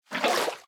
sounds / item / bucket / fill2.ogg